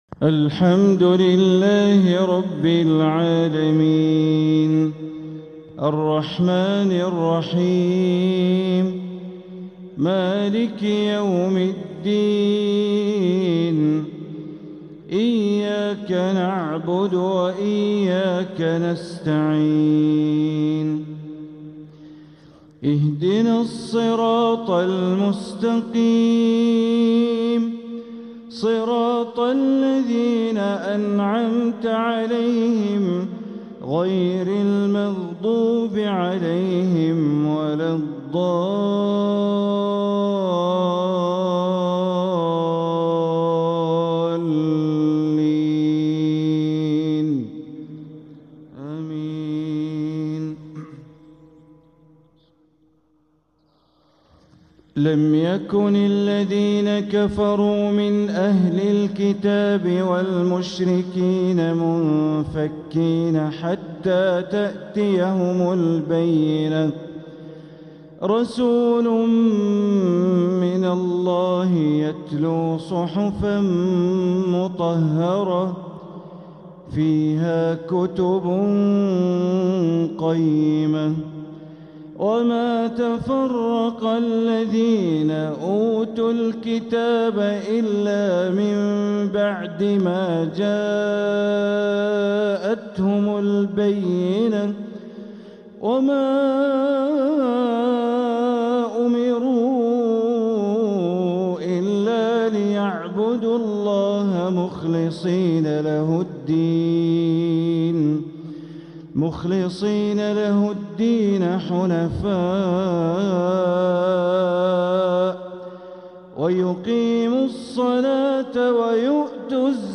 سلسلة اللآلئ الأصيلة لتلاوات الشيخ بندر بليلة لتلاوات شهر جمادى الأولى (الحلقة الواحدة والثمانون ) > سلسلة اللآلئ الأصيلة لتلاوات الشيخ بندر بليلة > المزيد - تلاوات بندر بليلة